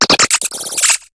Cri de Sonistrelle dans Pokémon HOME.